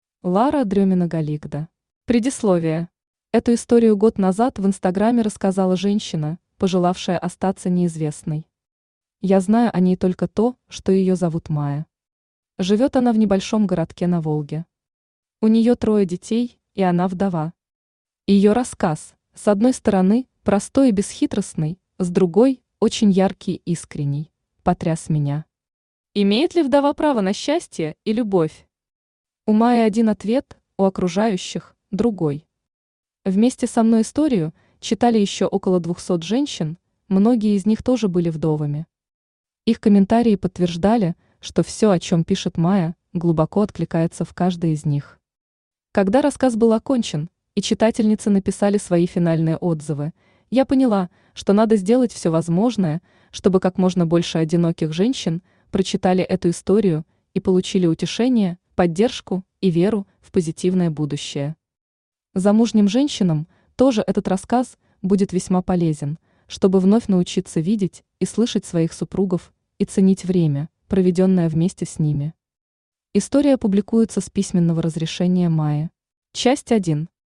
Aудиокнига Галигда Автор Лара Дрёмина Читает аудиокнигу Авточтец ЛитРес.